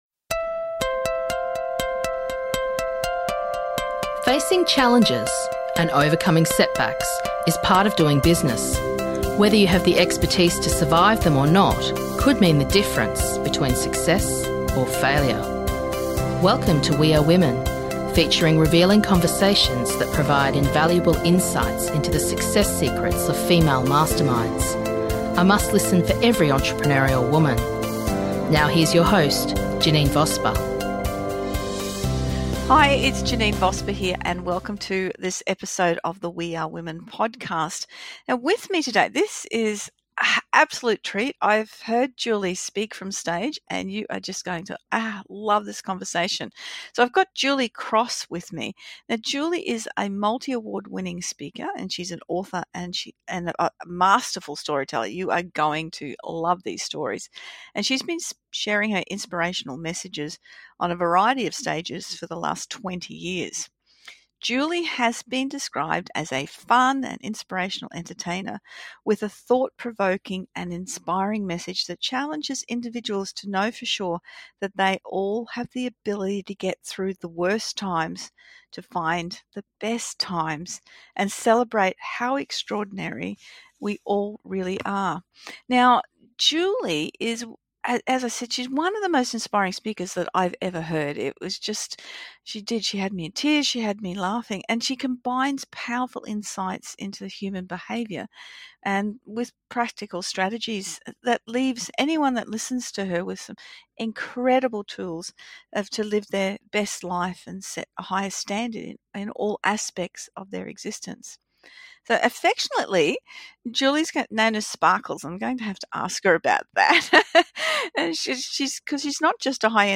We Are Women Podcast features revealing conversations that provide invaluable insights into the secrets of success of female masterminds.